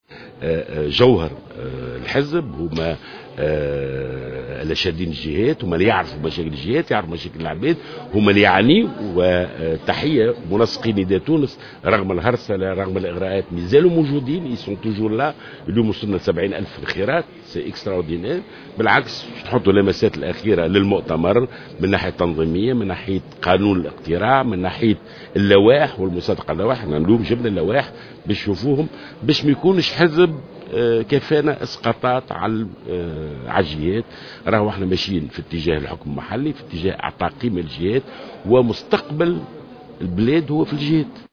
وأشاد جلول، في تصريح لمراسل الجوهرة أف أم، خلال اجتماع المكتب السياسي للنداء بالمنسقين الجهويين بولاية المنستير اليوم الخميس، بالمجهودات الكبيرة التي يقوم بها المنسقون الجهويون لحركة نداء تونس، من أجل الإعداد للمؤتمر، على الرغم من "الإغراءات والهرسلة" التي يواجهونها، بحسب تعبيره.